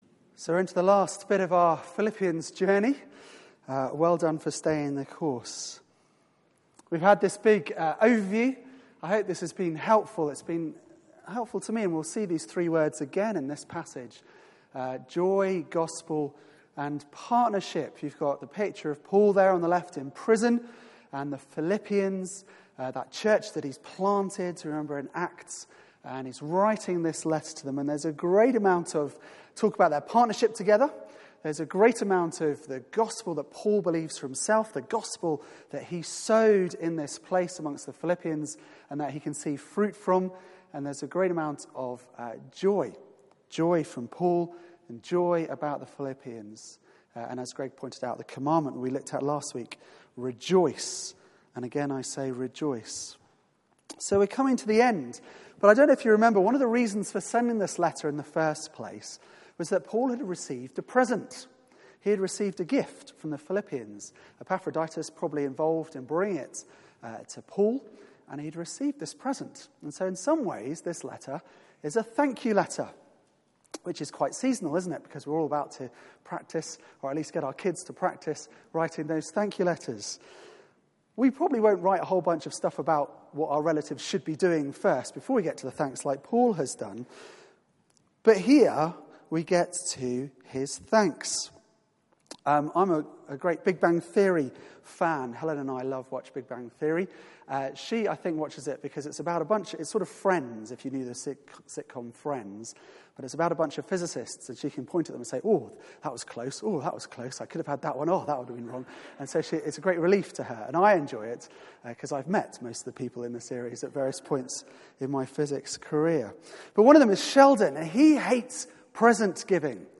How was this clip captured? Media for 4pm Service on Sun 29th Nov 2015 16:00 Speaker